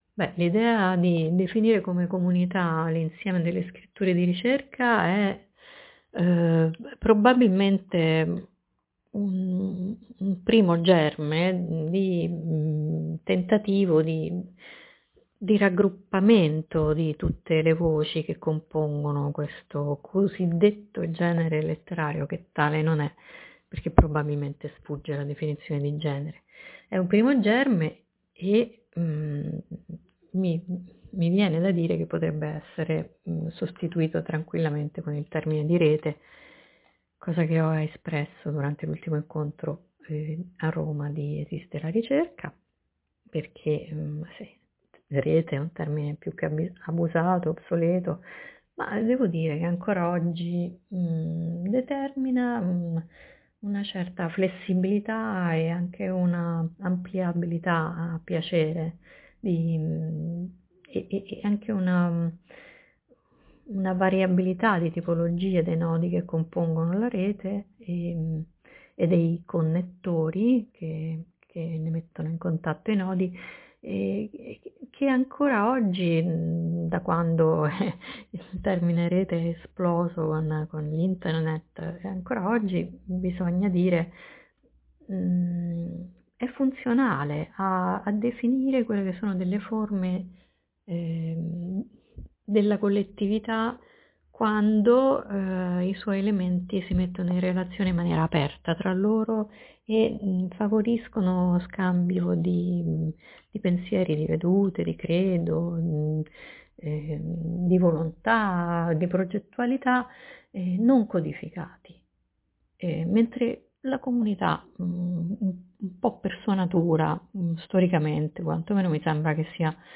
È lasciata la massima libertà nel rispondere (o non rispondere) via mp3, ma è richiesta una serie di condizioni in linea con la natura estemporanea di ELR: i vocali non devono essere ‘preparati’, né letti, né (poi) editati, né in sostanza pensati come elementi di un’esposizione calligrafica / accademica, bensì pronunciati al microfono e registrati così come nascono.